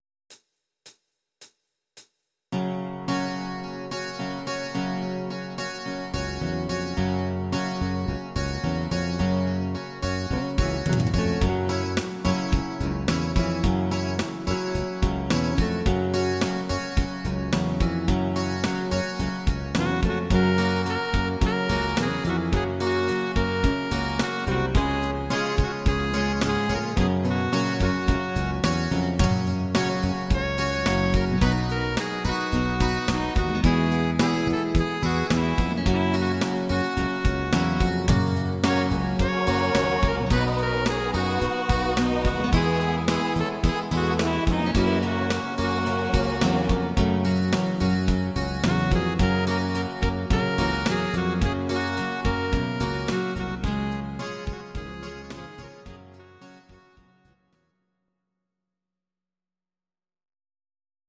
Audio Recordings based on Midi-files
Country, 1970s